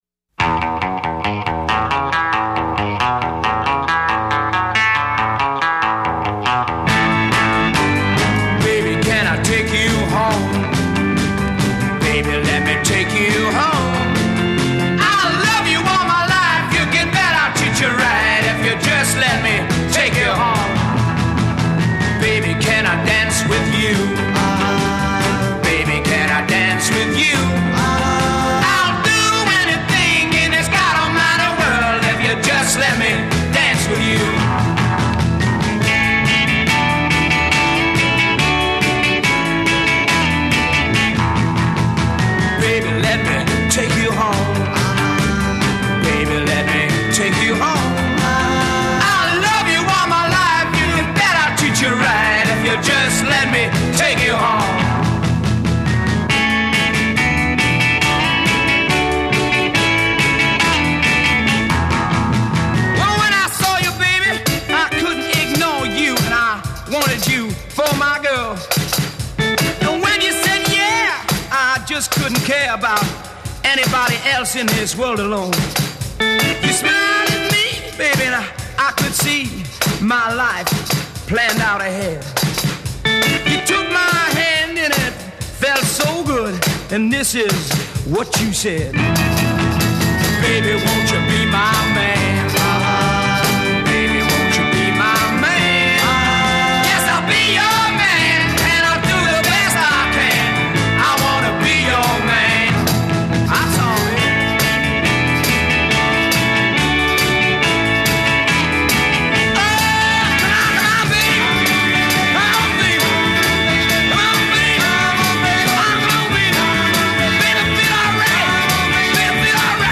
Recorded February 1964 at Kingsway Studios, London.
Intro 0:00 7 ½ + 2 guitar solo, begins on half measure
B bridge : 32 (4 x 8) recited word w/ drums & organ breaks c
outro : 31 vocal w/ chorus; gospel imitation e
British Blues